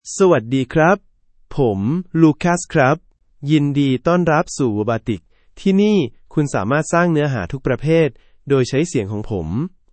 Lucas — Male Thai AI voice
Lucas is a male AI voice for Thai (Thailand).
Voice sample
Male
Lucas delivers clear pronunciation with authentic Thailand Thai intonation, making your content sound professionally produced.